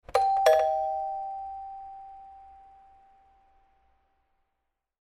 دانلود آهنگ زنگ در 4 از افکت صوتی اشیاء
دانلود صدای زنگ در 4 از ساعد نیوز با لینک مستقیم و کیفیت بالا
جلوه های صوتی